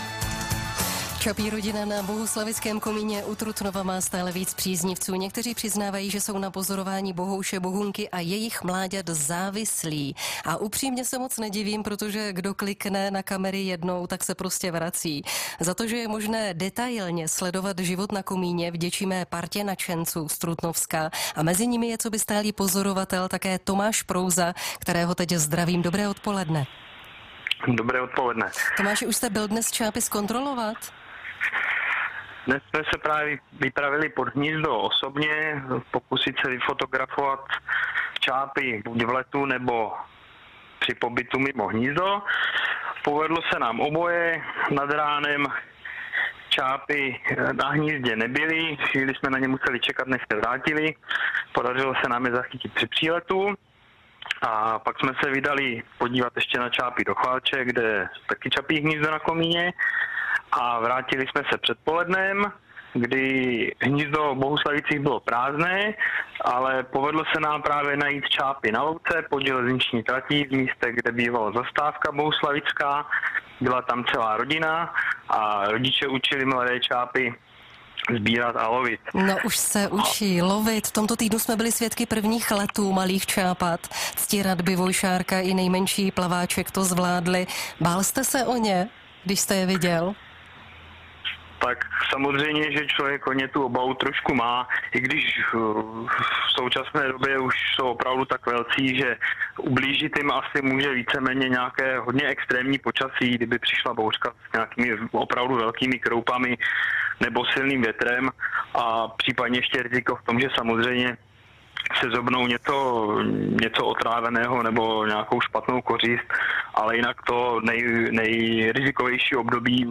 Záznam rozhovoru o bohuslavickém čapím hnízdě v Českém rozhlase Hradec Králové